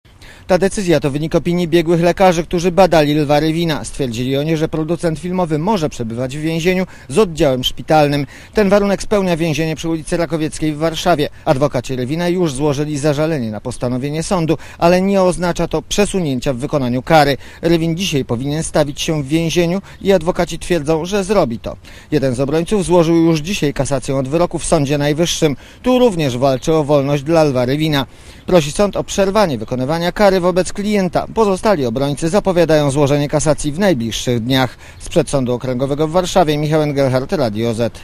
Relacja reportera Radia ZET Rywin podjechał przed więzienie samochodem bmw w asyście ochroniarza, po czym - unikając wypowiedzi dla licznie zgromadzonych dziennikarzy - wszedł do zakładu karnego.